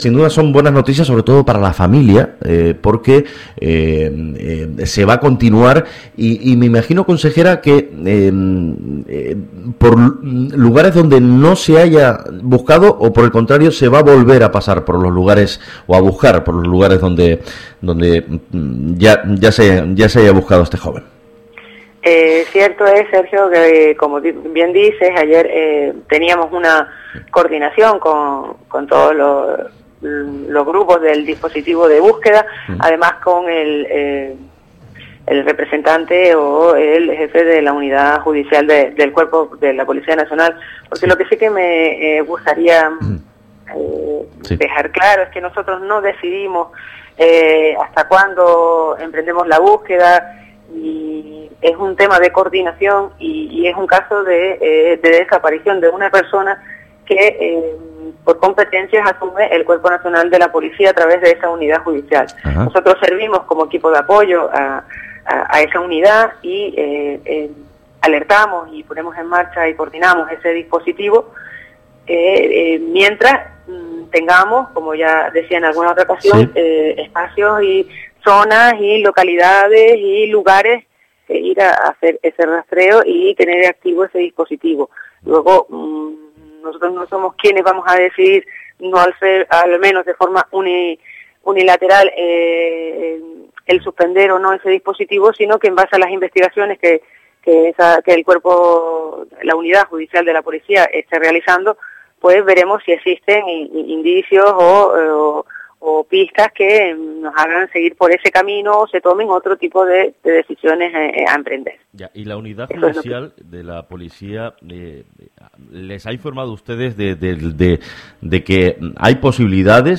Audio: Lancelot Radio Onda Cero